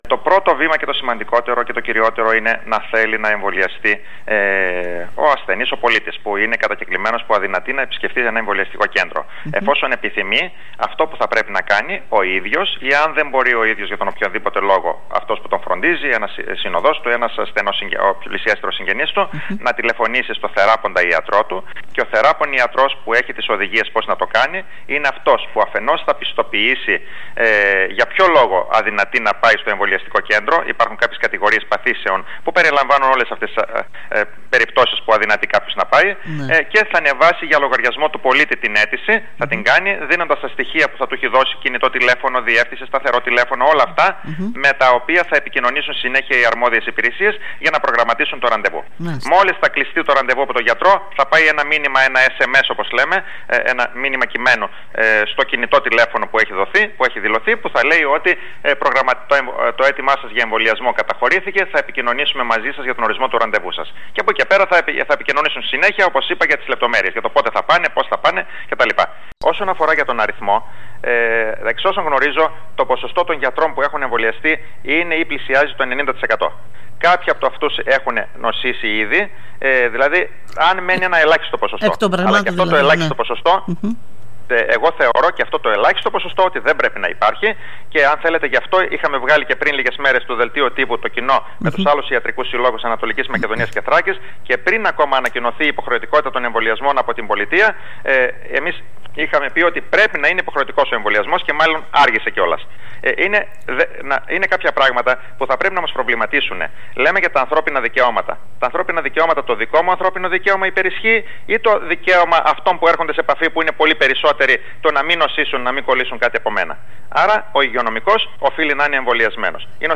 Μιλώντας σήμερα στην ΕΡΤ Ορεστιάδας